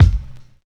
• Small Reverb Kick Drum Single Shot F# Key 282.wav
Royality free kickdrum tuned to the F# note. Loudest frequency: 215Hz
small-reverb-kick-drum-single-shot-f-sharp-key-282-muH.wav